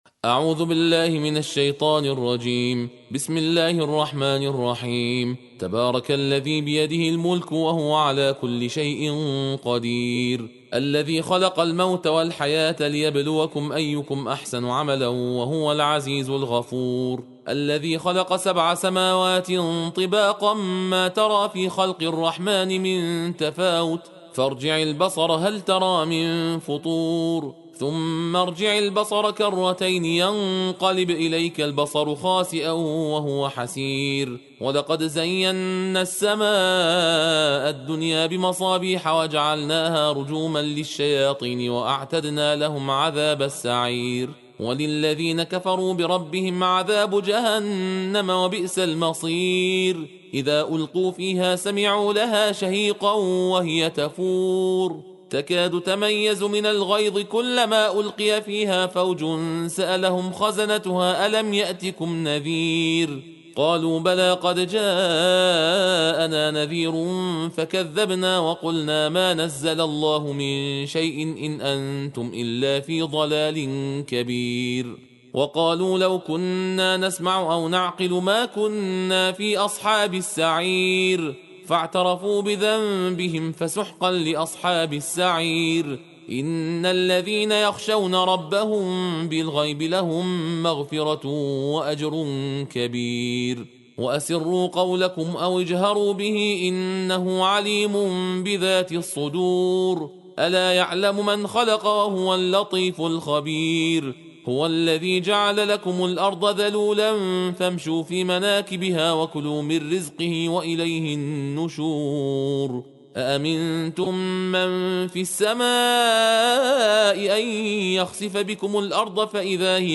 جزء بیست و نهم قرآن صوتی تندخوانی با متن و ترجمه درشت جز 29 - ستاره